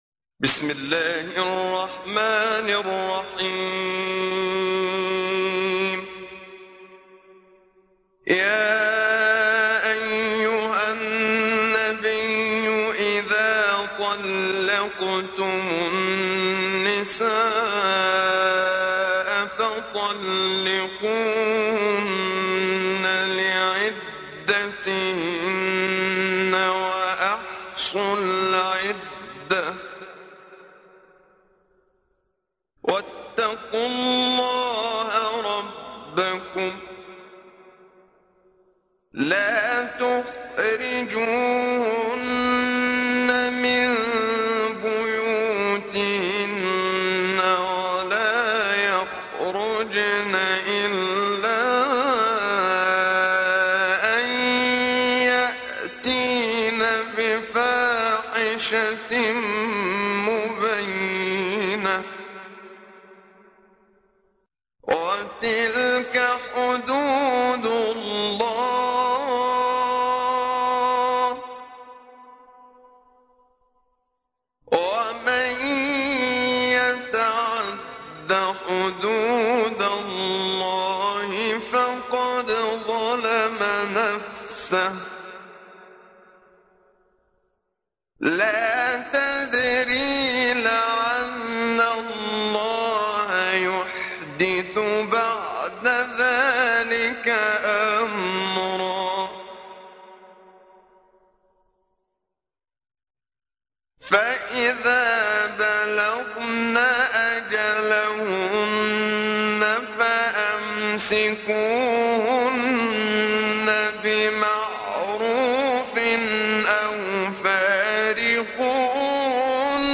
تحميل : 65. سورة الطلاق / القارئ محمد صديق المنشاوي / القرآن الكريم / موقع يا حسين